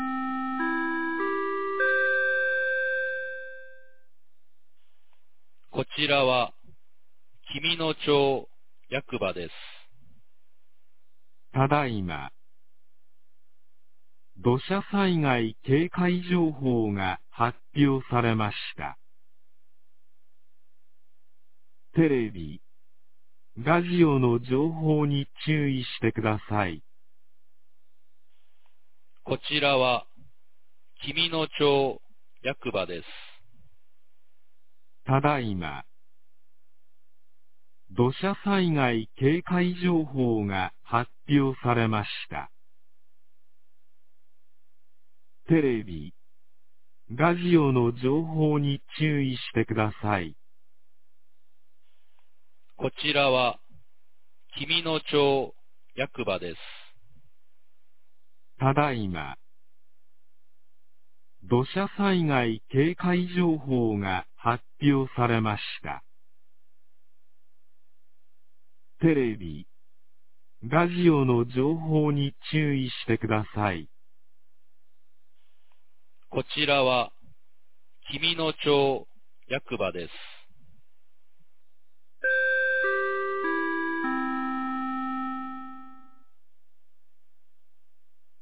2023年06月02日 11時27分に、紀美野町より全地区へ放送がありました。
放送音声